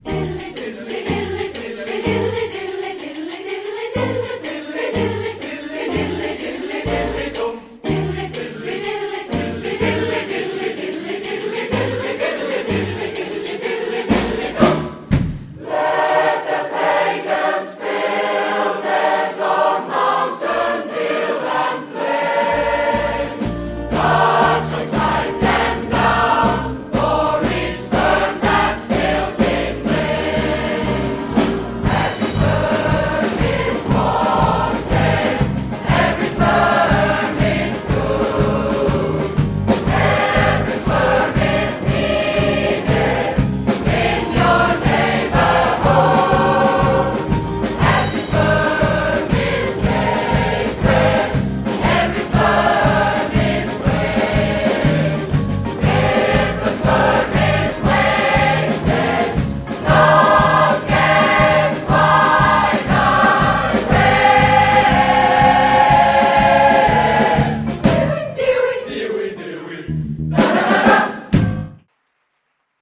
Band , SATB & Solo M/W